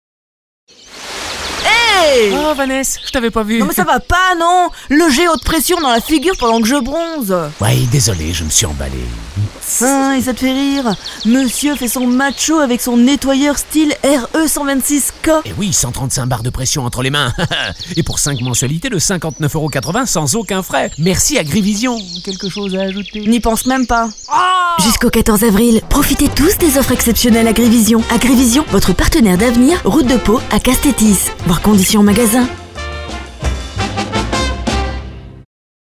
4000+ exemples de spots radio